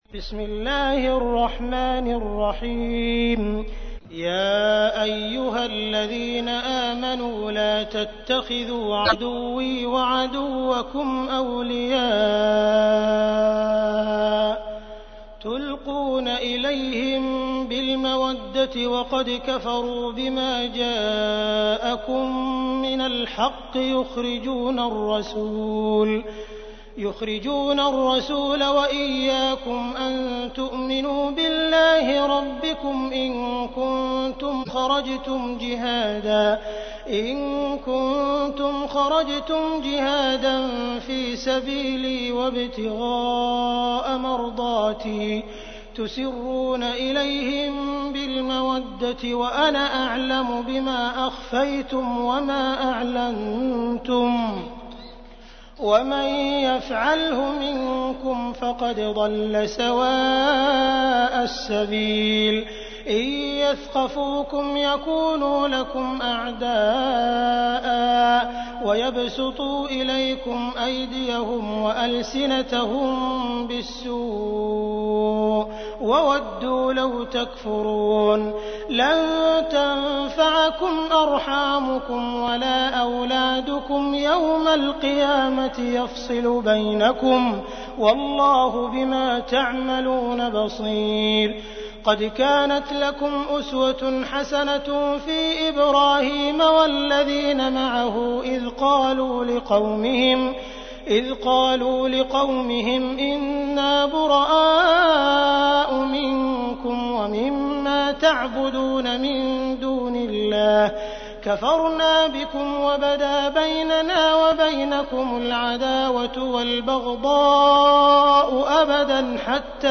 تحميل : 60. سورة الممتحنة / القارئ عبد الرحمن السديس / القرآن الكريم / موقع يا حسين